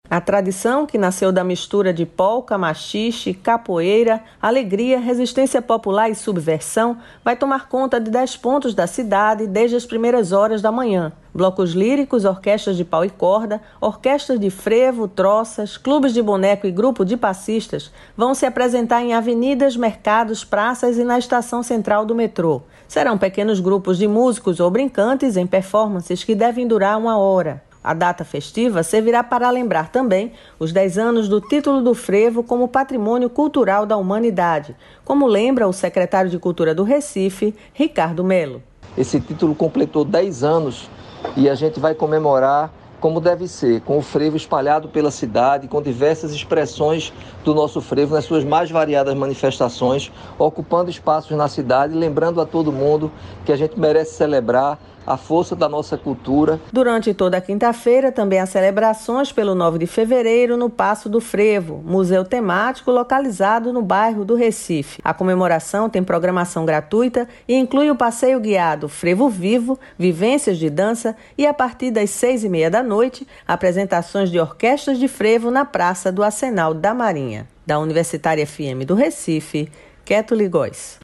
A data festiva servirá para lembrar também os dez anos do título do frevo como Patrimônio Cultural da Humanidade, como lembra o secretário de Cultura do Recife, Ricardo Mello.